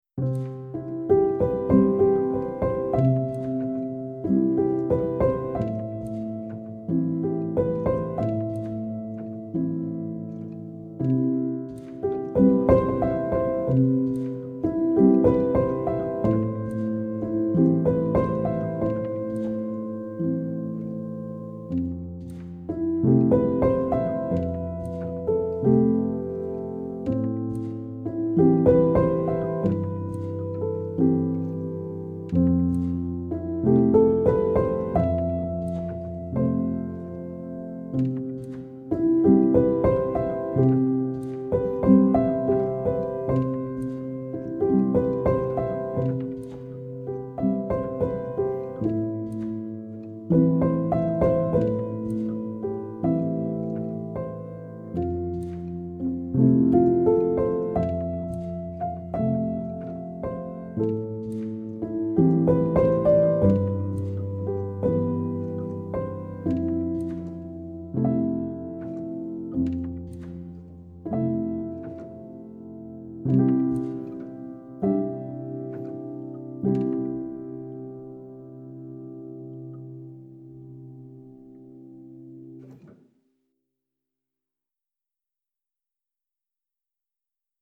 آرامش بخش , پیانو , مدرن کلاسیک , موسیقی بی کلام